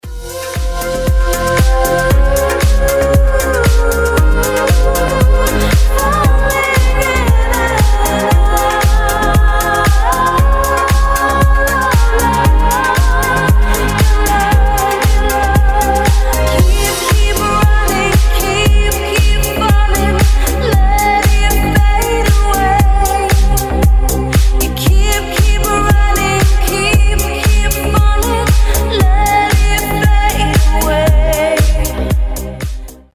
• Качество: 320, Stereo
женский вокал
remix
deep house
nu disco